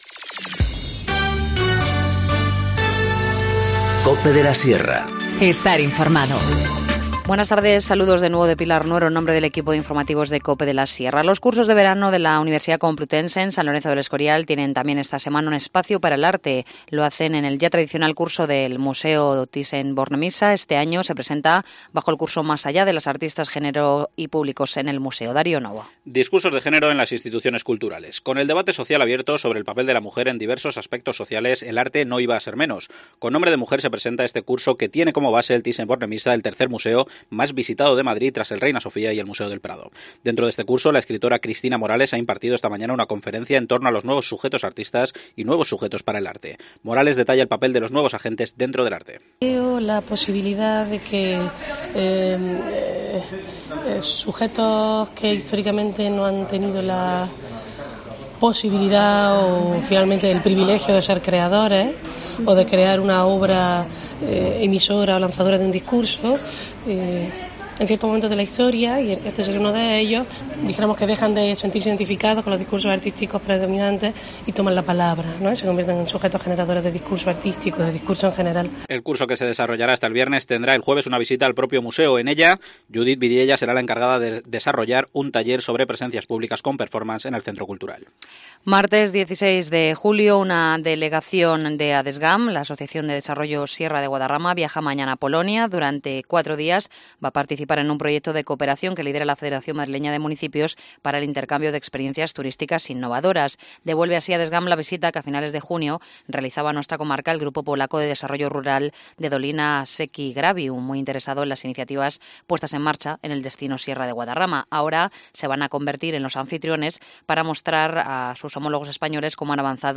Informativo Mediodía 16 julio 14:50h